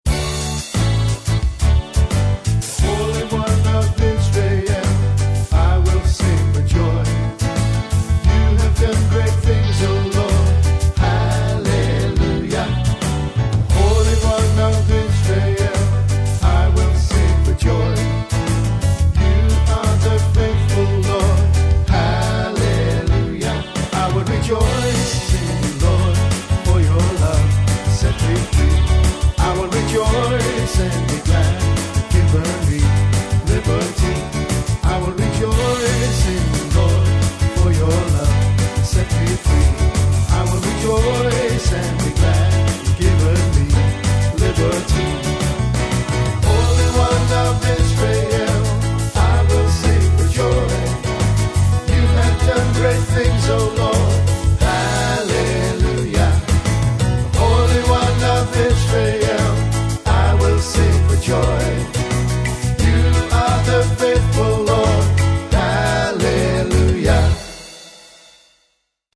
Funky exhortation